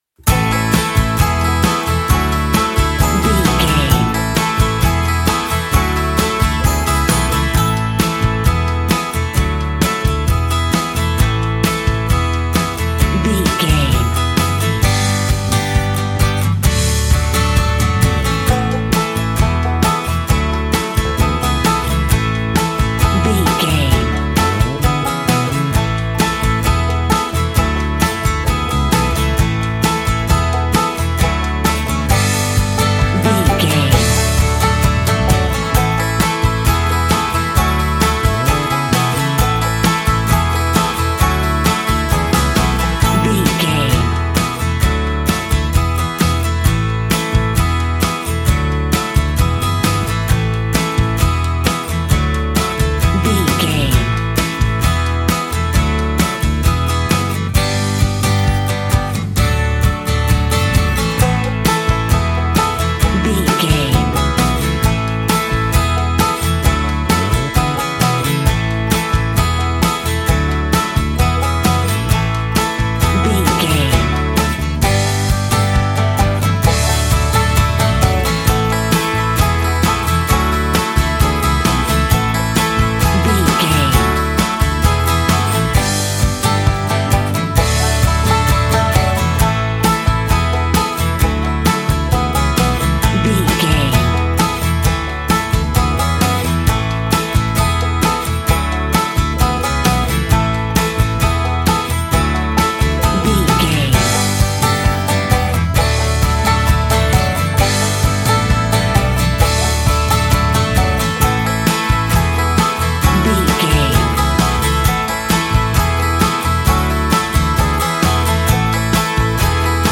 Ionian/Major
Fast
drums
electric guitar
bass guitar
banjo
acoustic guitar